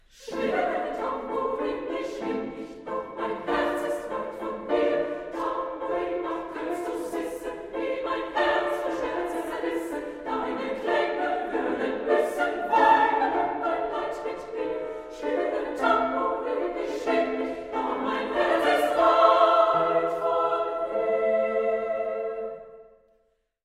chormusikalisches A-cappella-Werk
für gemischten Chor und Frauenstimmen